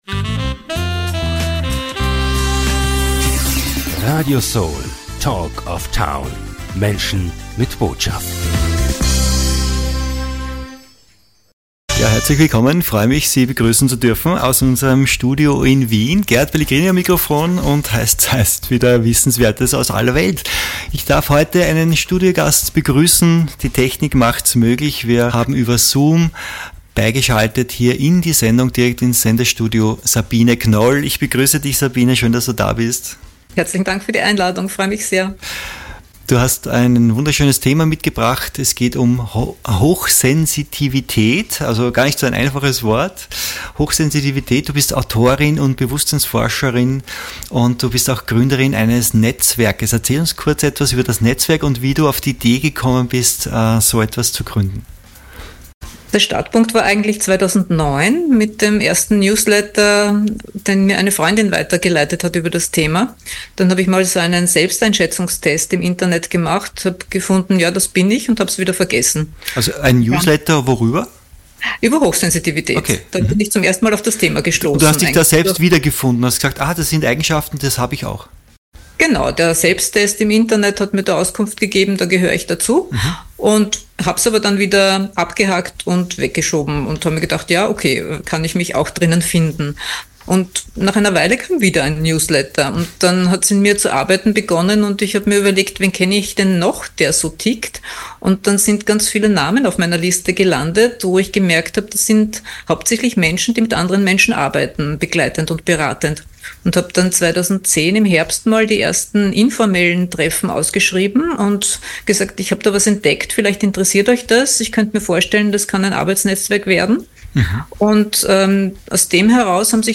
Hochsensitivität im Gespräch